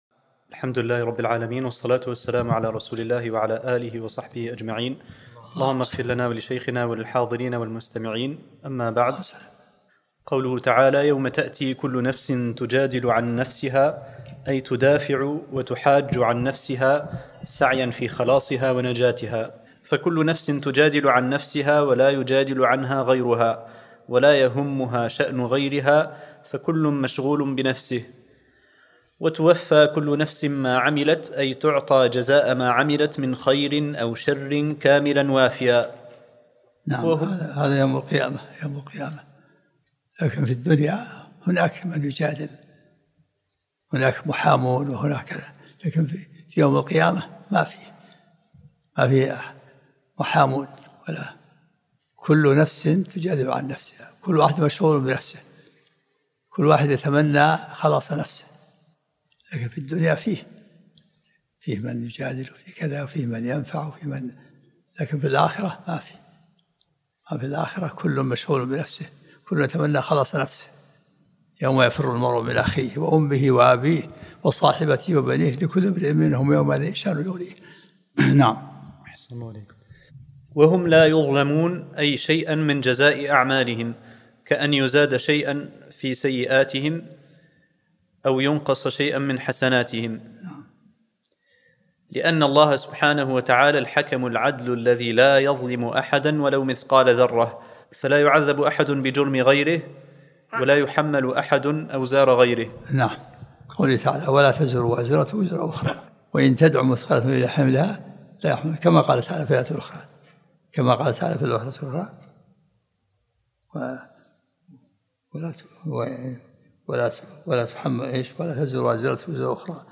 الدرس الرابع عشر من سورة النحل